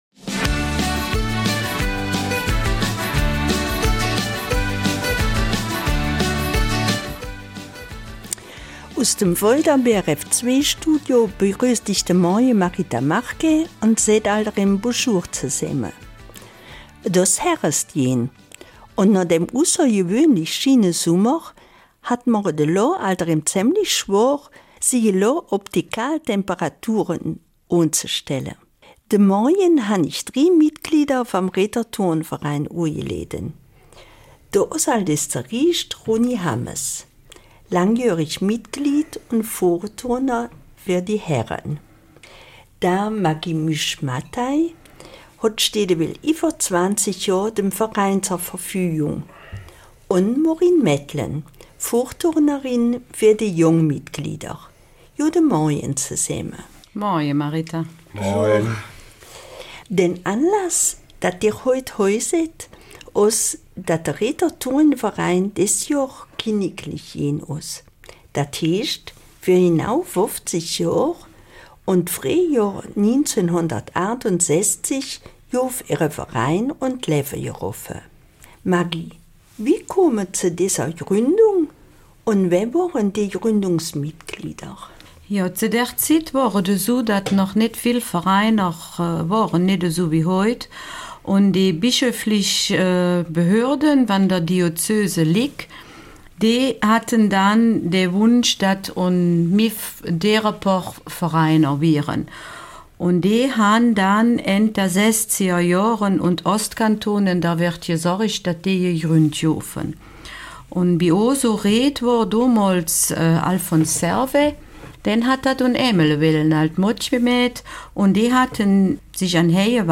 Eifeler Mundart: 50 Jahre KTSV Recht